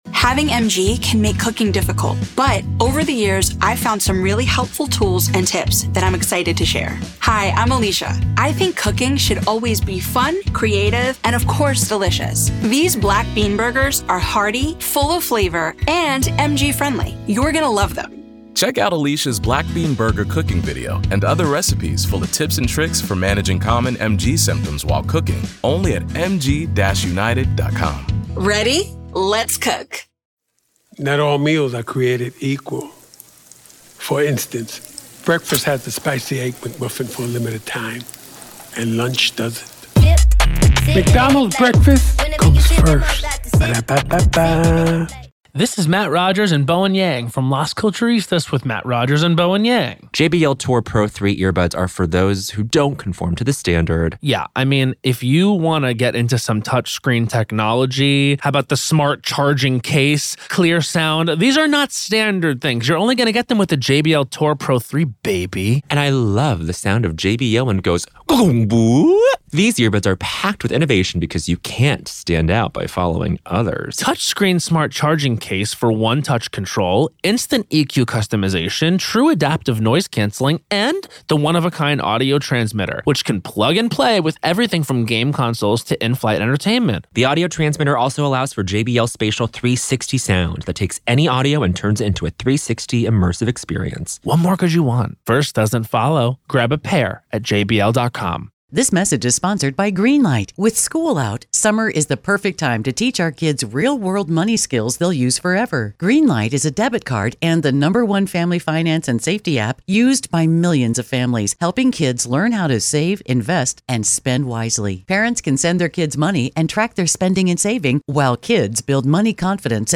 Their dialogue unveils the uncomfortable realities lurking behind the sterile facade of forensic analysis.